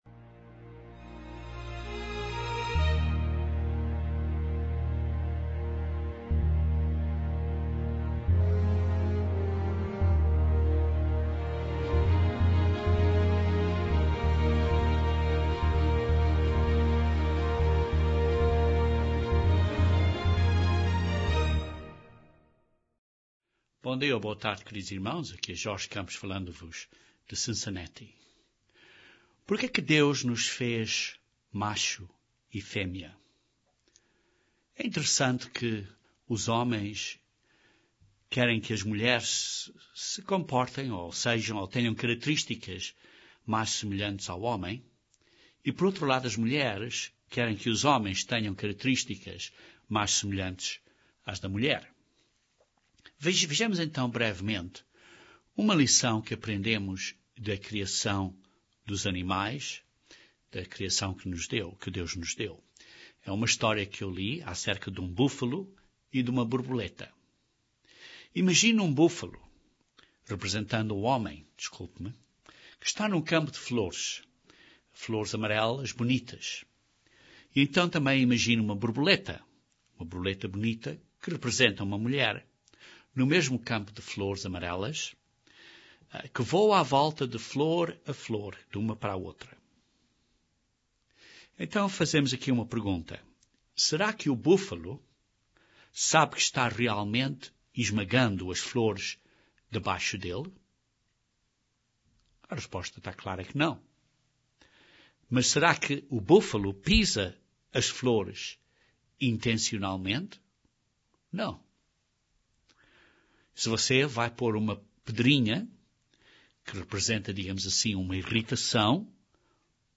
Este é o segundo de dois sermões acerca das necessidades básicas do homem e da mulher. Este sermão cobre cinco necessidades do homem.